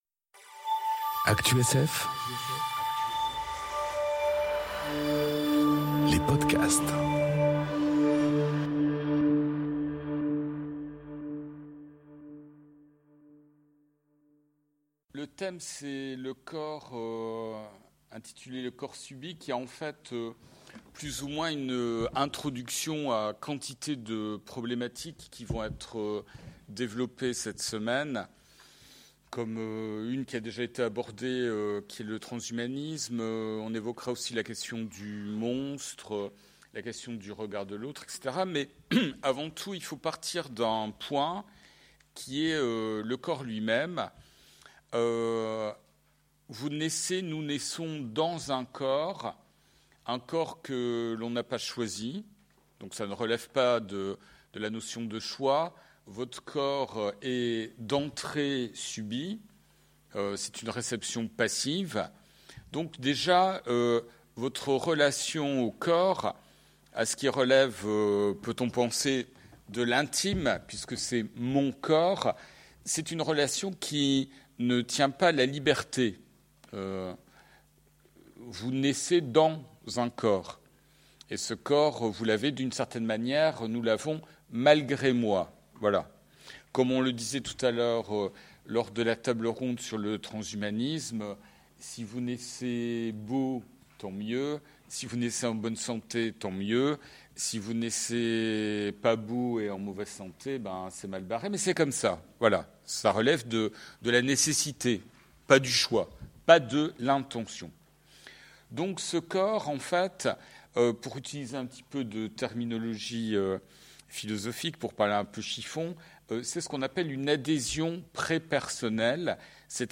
Conférence Le corps subi enregistrée aux Utopiales 2018